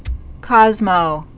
(kŏzmō)